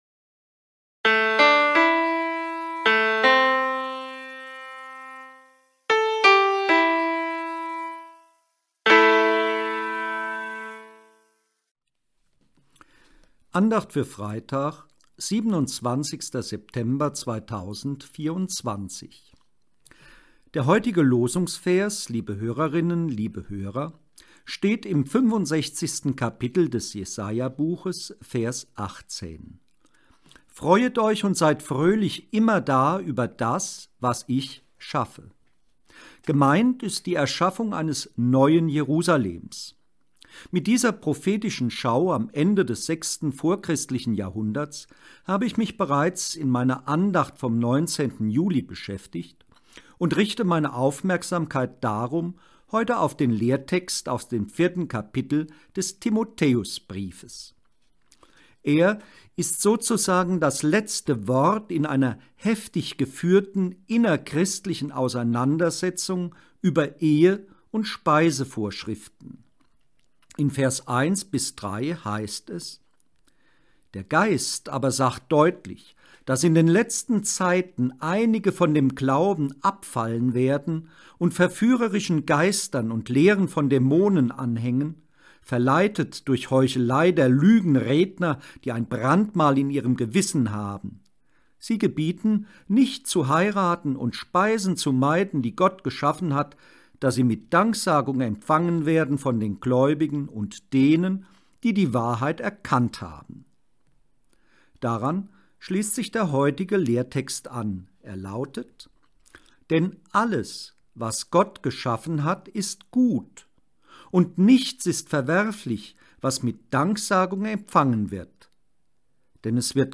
Losungsandacht